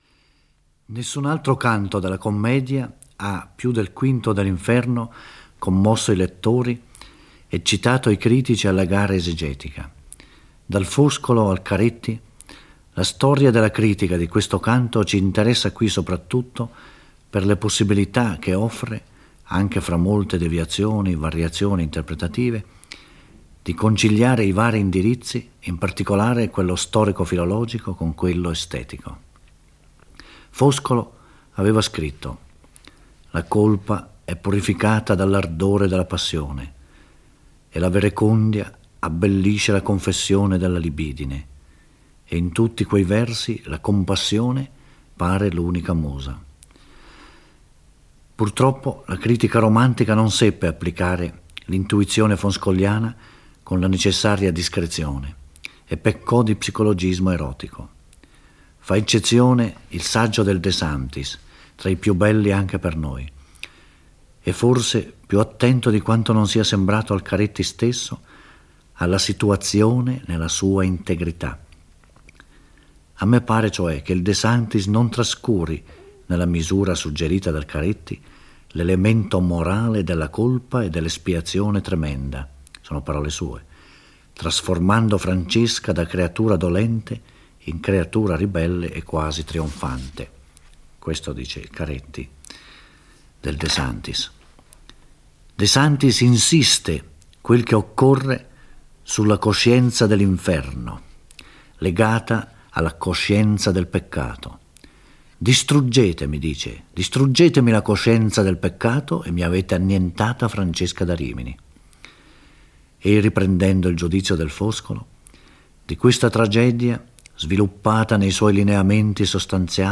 Giorgio Orelli legge e commenta il V canto dell'Inferno. Ci troviamo nel secondo cerchio, luogo di condanna per i lussuriosi, uomini e donne che hanno anteposto al dovere il proprio piacere personale.